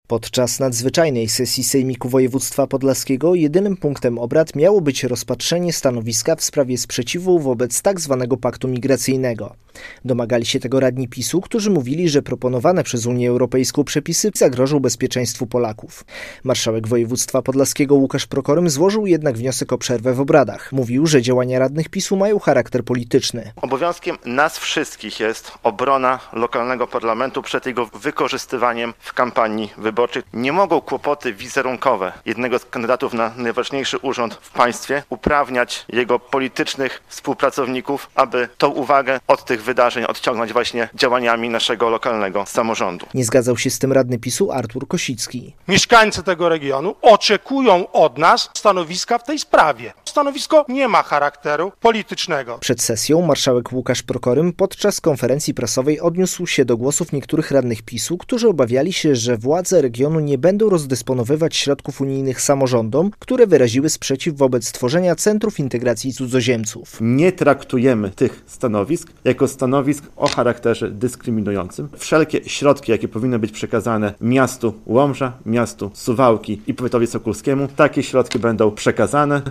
Nadzwyczajna sesja Sejmiku Województwa Podlaskiego - relacja